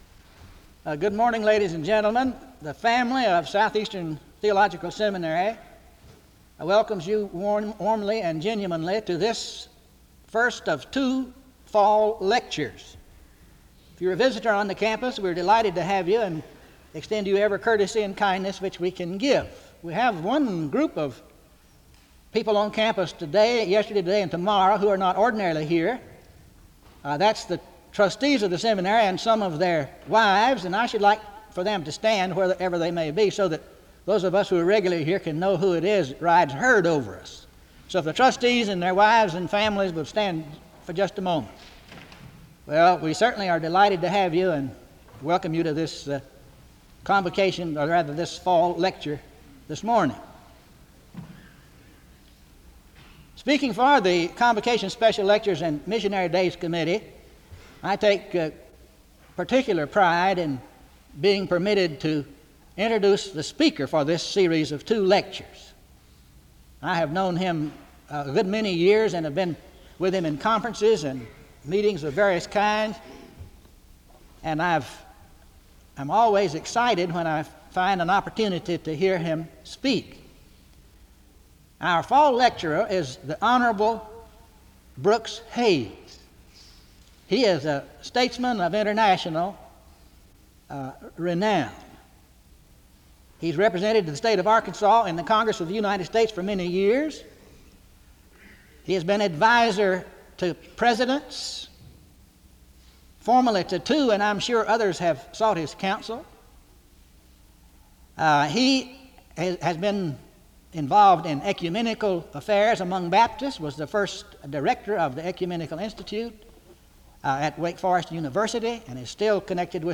File Set | SEBTS_Fall_Lecture_Brooks_Hays_1976-10-12.wav | ID: 15f3df4d-e988-4e57-8af4-e5e890baf8eb | Hyrax